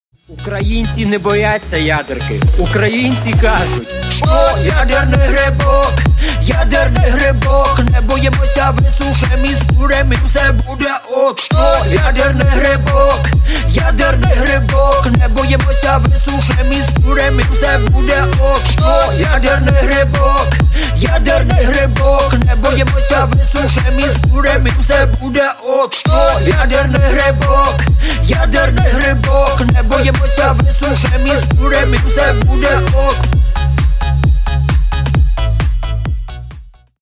Клубные Рингтоны » # Весёлые Рингтоны
Поп Рингтоны » # Танцевальные Рингтоны